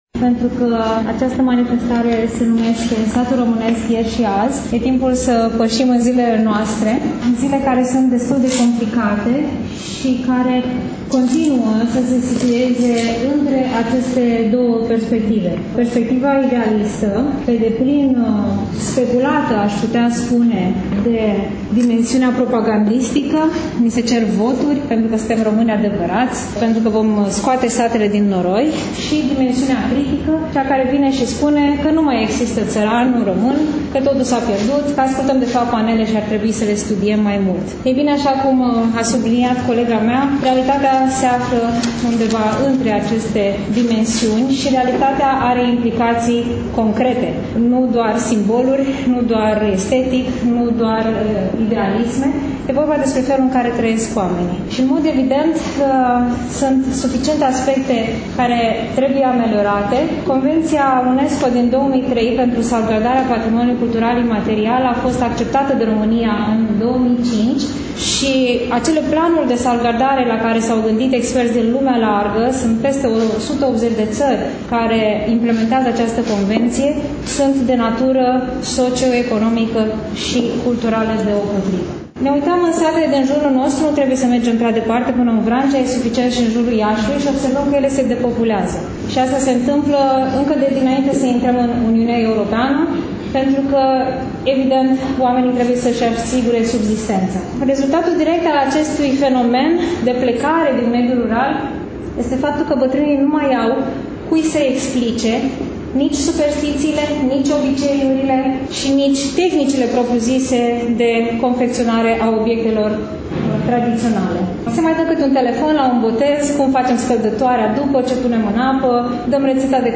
Astăzi, după cum bine știți, relatăm de la expoziţia de carte „Satul românesc”, manifestare culturală desfășurată, nu demult, la Iași, în incinta Bibliotecii Centrale Universitare „Mihai Eminescu”.